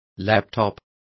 Complete with pronunciation of the translation of laptop.